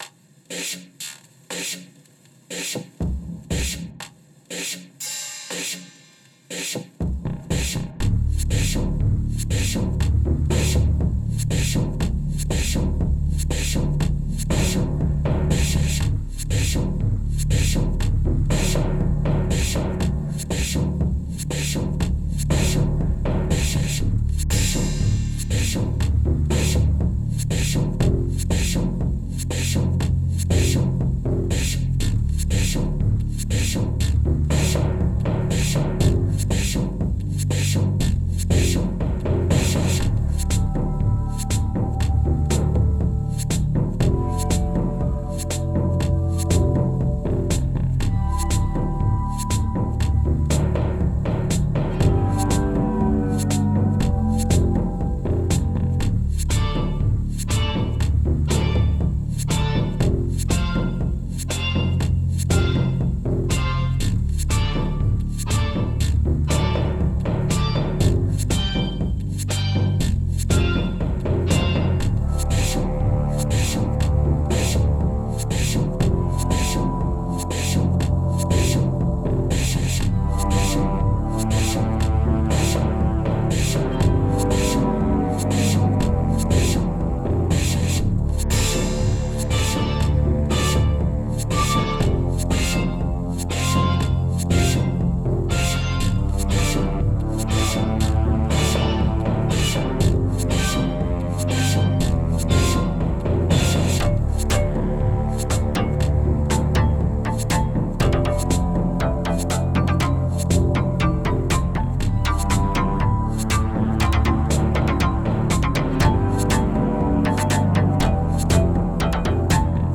Andere werden reflektiert, bringen einige kleine noch glänzende Metallteile zum schwingen. Sie verbinden und formieren sich zu einem rhythmischen Gebilde ...